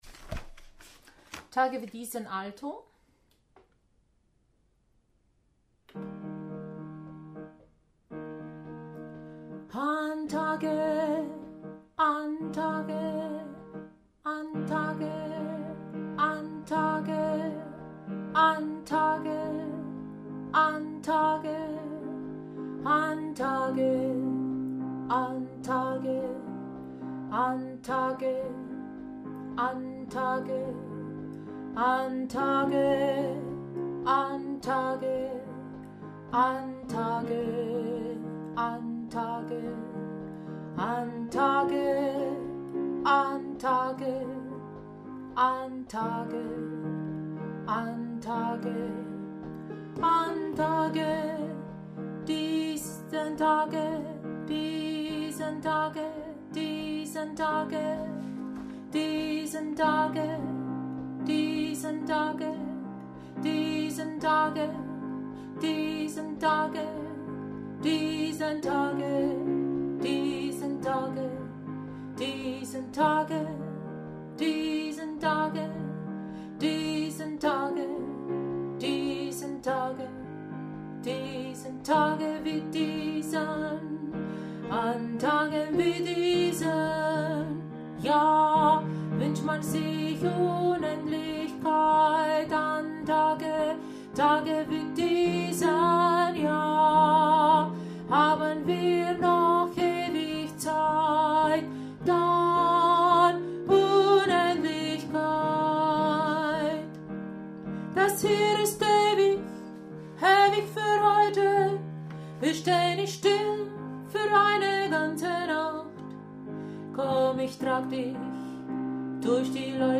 Tage wie diese – Alto